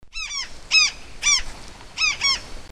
Large-billed Tern (Phaetusa simplex)
Life Stage: Adult
Location or protected area: Estancia Buena Vista
Condition: Wild
Certainty: Photographed, Recorded vocal
Phaetusasimplex.mp3